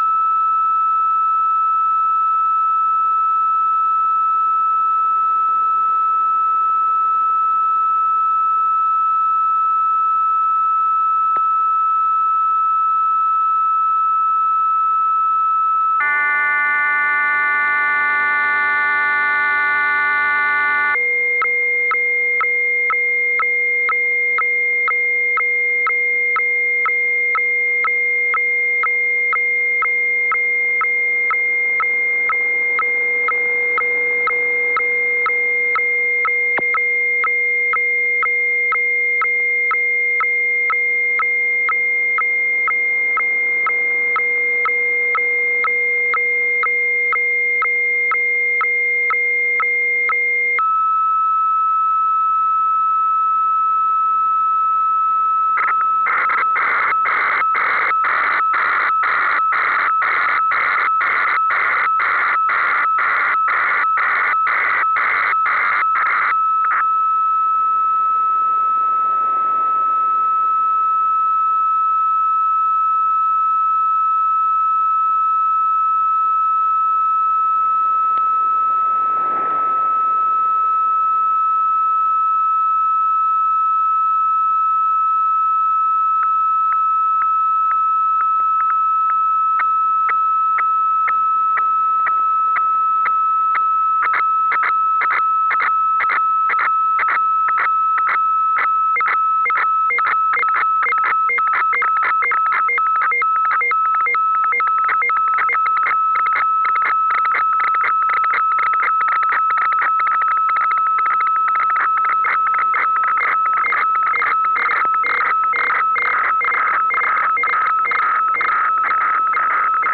A sampled HF fax transmission:
fax_signal.au